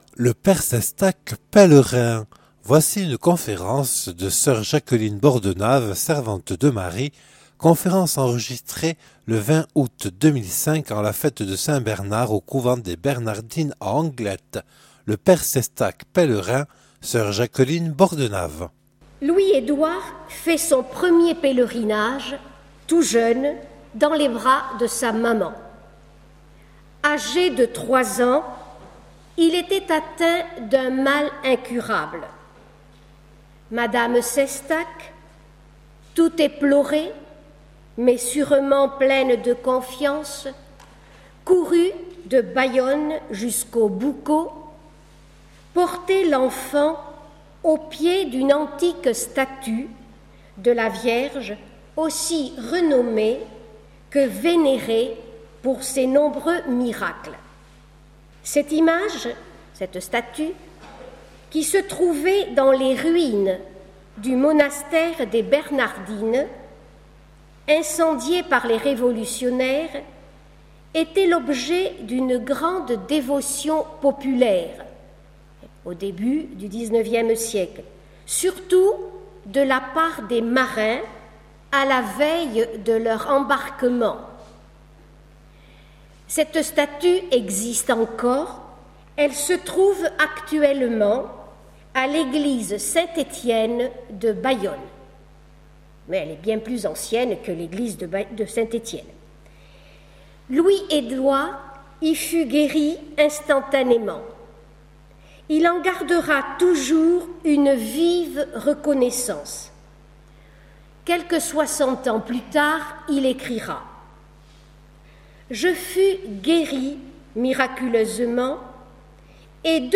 (Enregistrée le 20/08/2005 au couvent des Bernardines à Anglet).